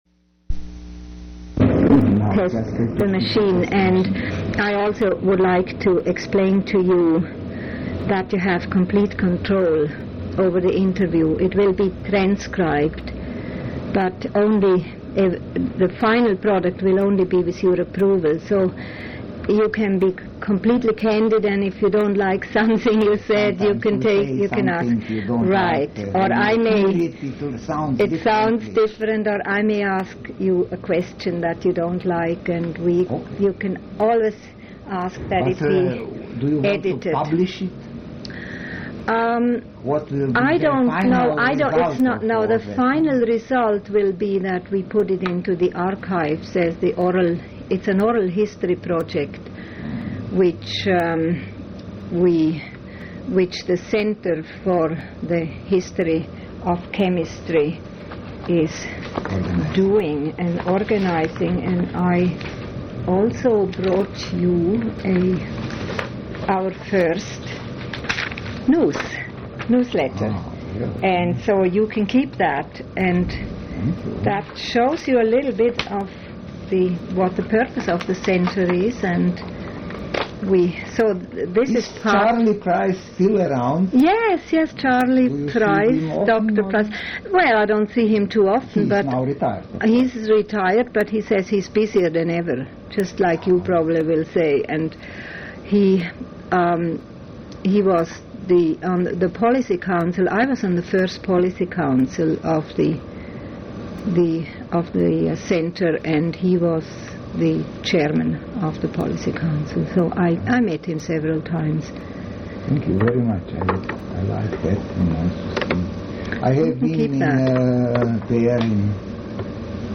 Oral history interview with Vladimir Prelog
Place of interview Eidgenössische Technische Hochschule Zürich